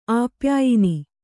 ♪ āpyāyini